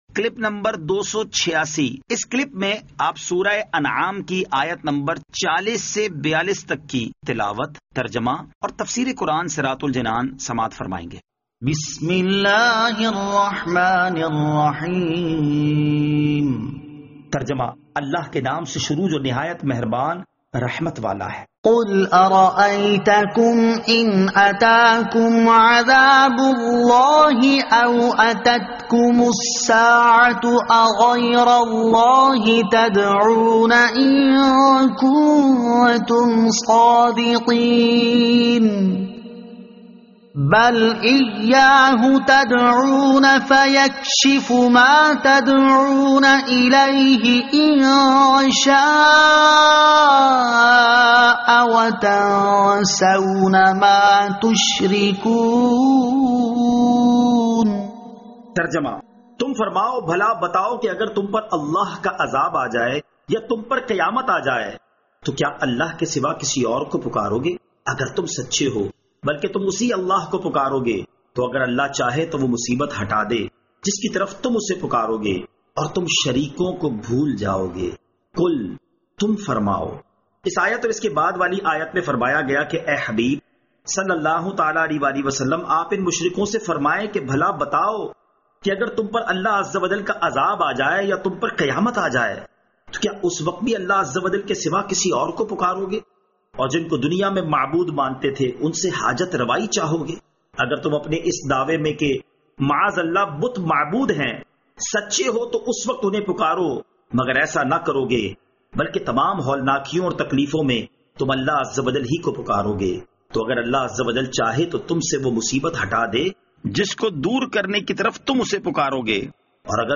Surah Al-Anaam Ayat 40 To 42 Tilawat , Tarjama , Tafseer